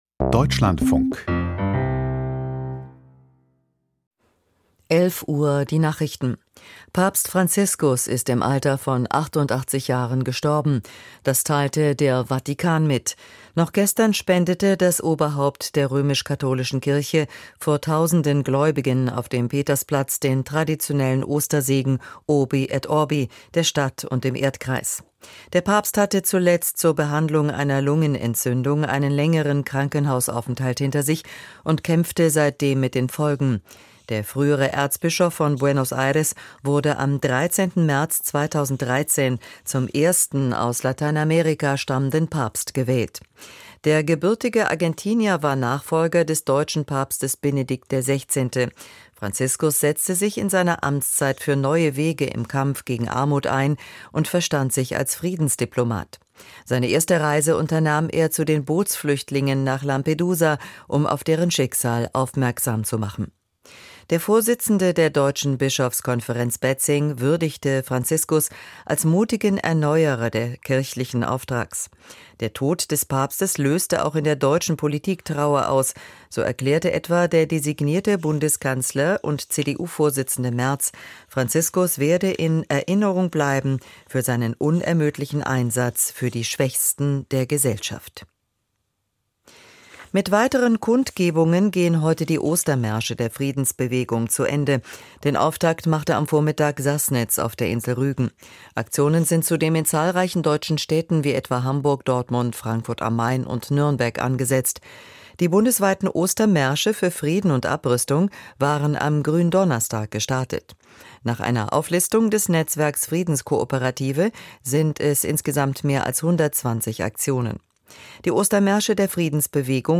Die Deutschlandfunk-Nachrichten vom 21.04.2025, 11:00 Uhr